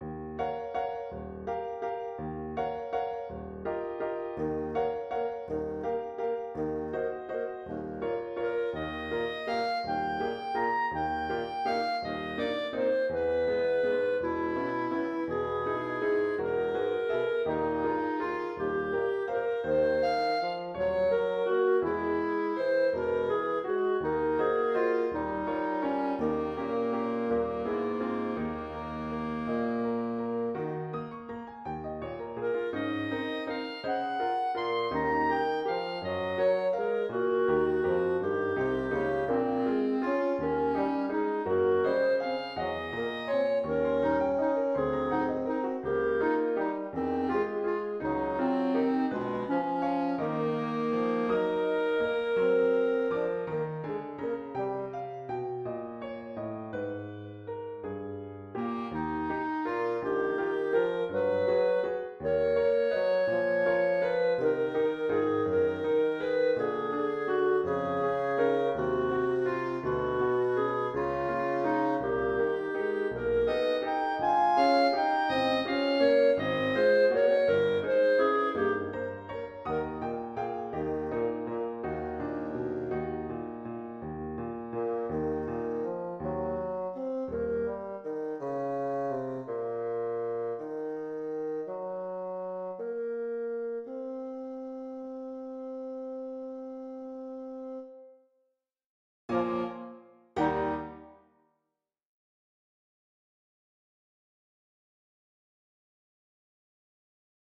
pour piano, basson et clarinette en si bémol
Elle se termine par une sorte de cadence parfaite dissonante qui en résume l’esprit général.